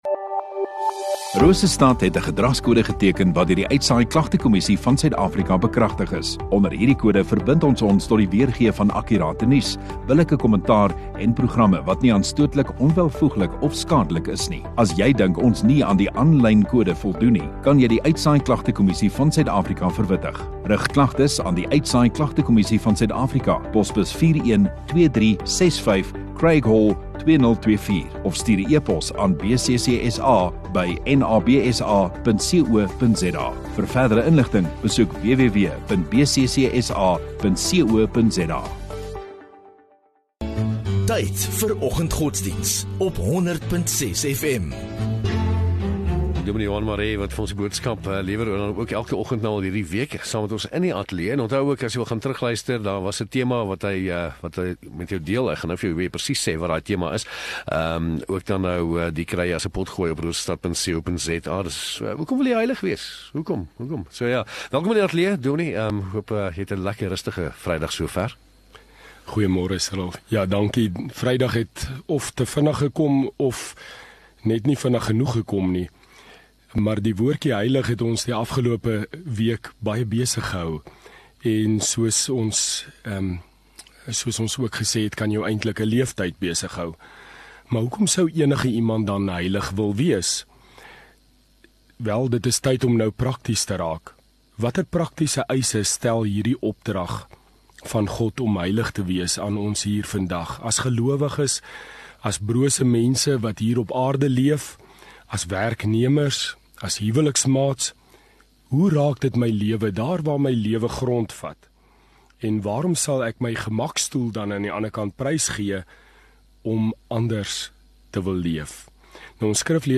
12 Jul Vrydag Oggenddiens